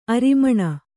♪ arimaṇa